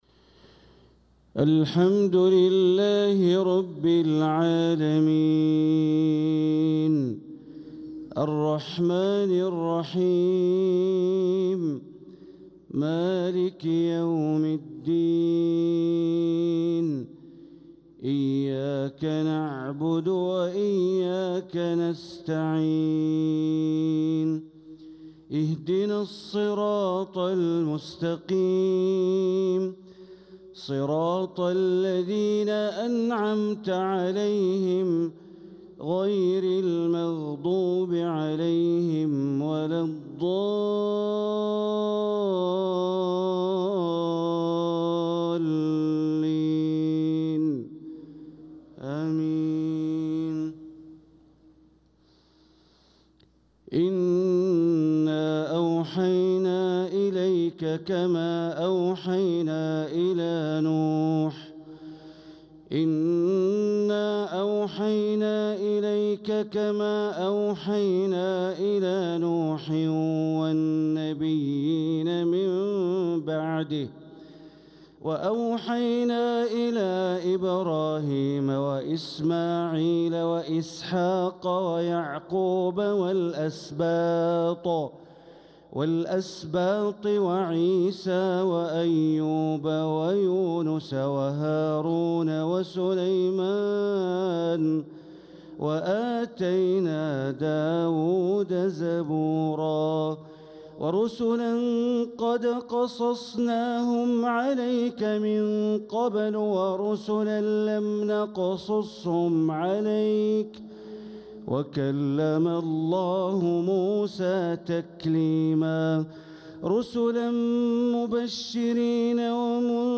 صلاة الفجر للقارئ بندر بليلة 18 ربيع الآخر 1446 هـ